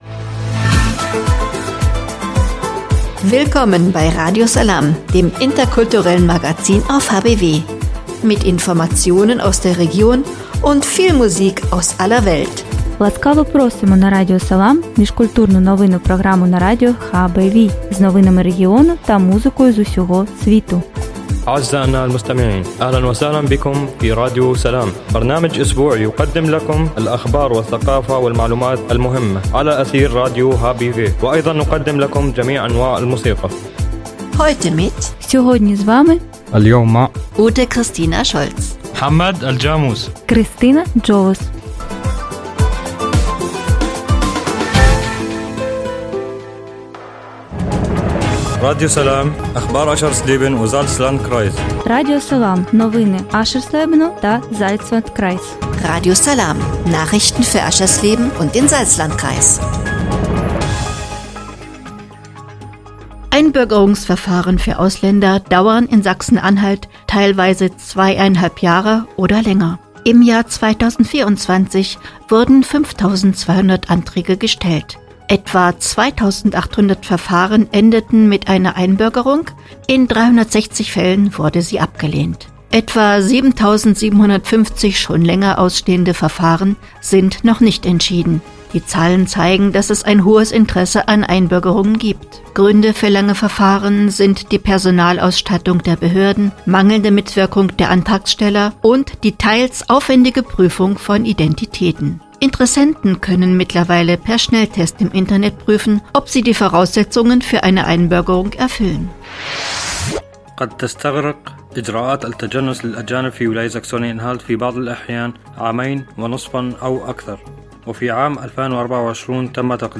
„Radio Salām“ heißt das interkulturelle Magazin auf radio hbw. Mit dem Wochenmagazin wollen die Macher alte und neue Nachbarn erreichen: diejenigen, die schon lange in Harz und Börde zu Hause sind, und ebenso Geflüchtete, beispielsweise aus Syrien.
Immer am Sonntag ab 19 Uhr und am Montag ab 9 Uhr werden Themen für Harz und Börde besprochen – sowohl auf Deutsch als auch in der Muttersprache der ehrenamtlichen Redakteure.